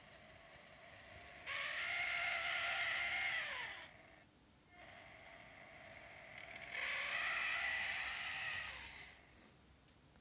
Efterfølgende havde jeg motoren ude for at checke kullene, og derefter kommer der nu en vældig støjende vibrerende lyd ved bare svagt hurtigt kørsel (wav vedhæftet).
Den støjer mere i sving end ligeud, og mere i den ene kørselsretning end i den anden.
ICE3_larmer.wav